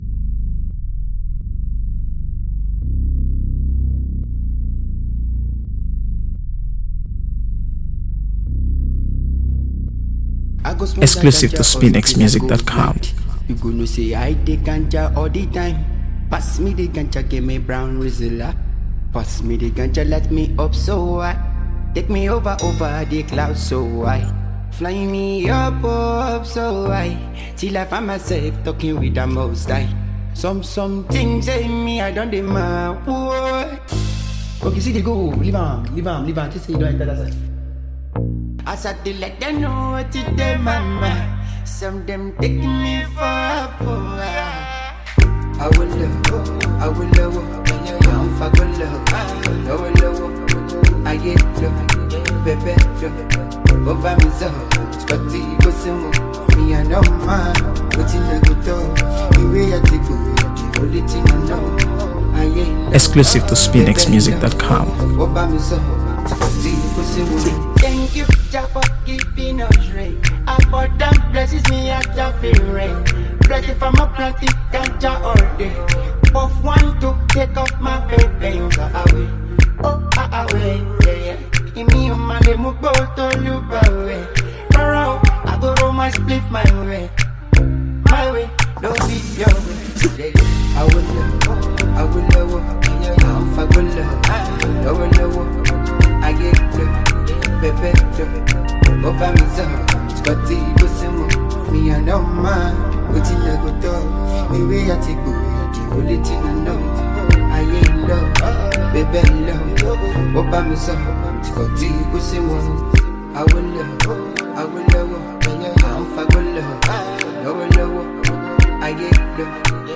AfroBeats | AfroBeats songs
This warm and intriguing tune